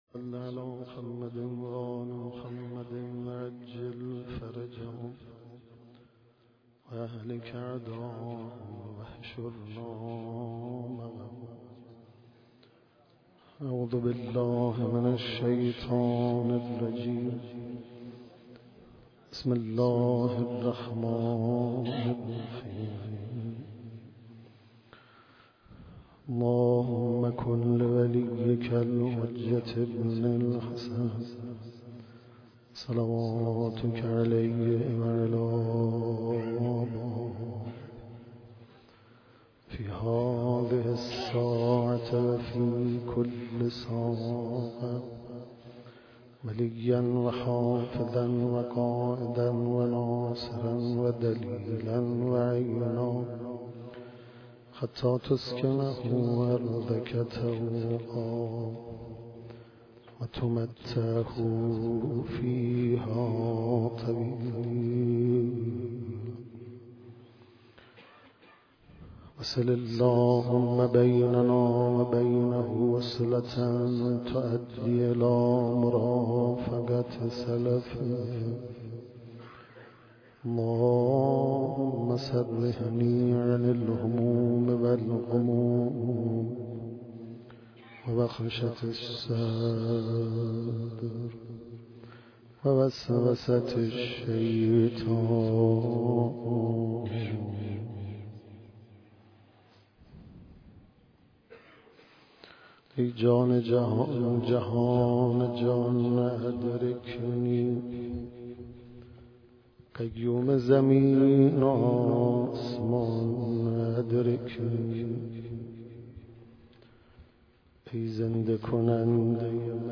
بیت الحسین/